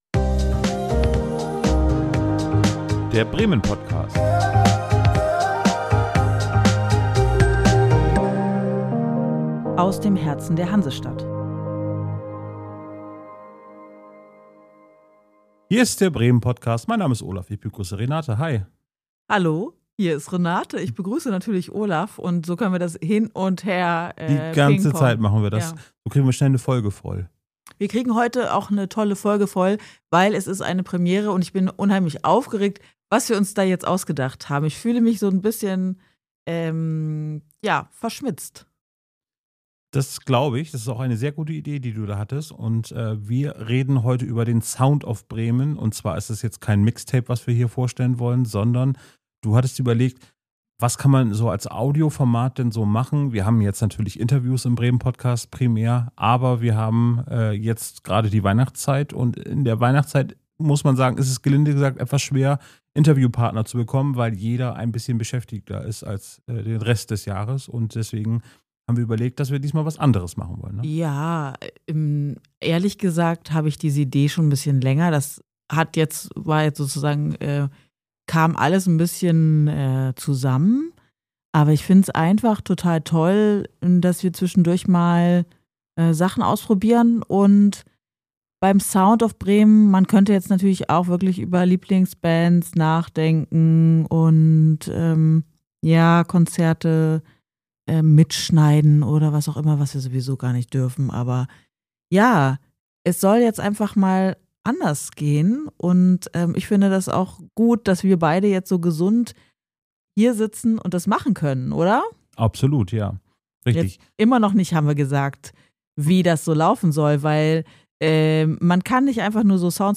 In ihrem Soundbattle gilt es, vier typische Bremer Geräusche zu erraten.